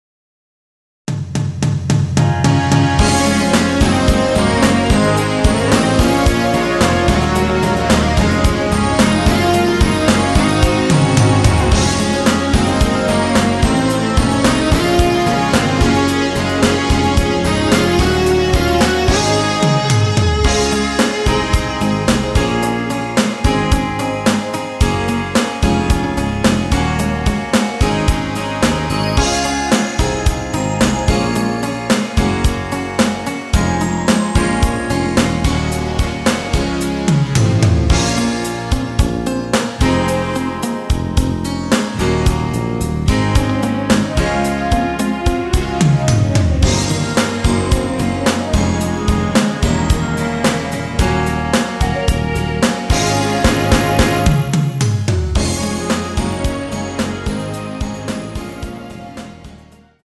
한키 내린 MR
D
앞부분30초, 뒷부분30초씩 편집해서 올려 드리고 있습니다.
중간에 음이 끈어지고 다시 나오는 이유는